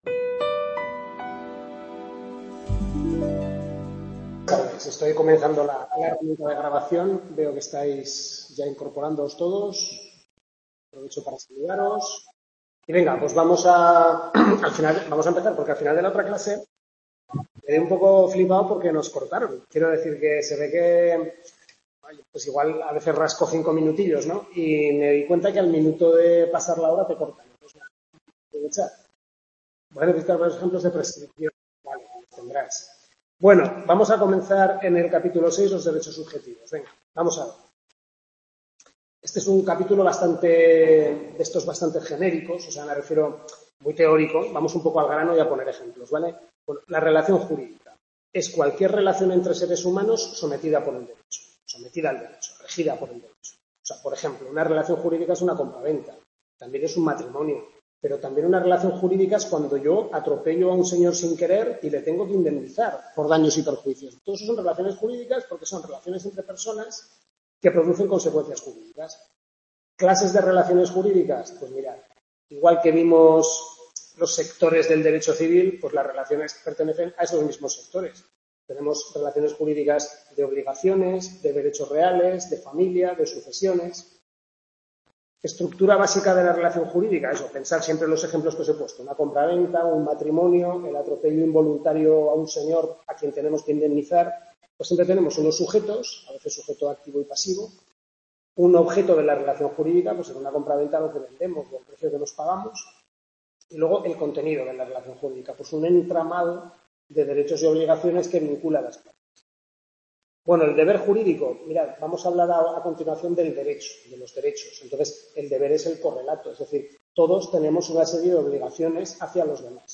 Tutoría 2/6 Civil I primer cuatrimestre (Parte General).- Centro UNED Calatayud.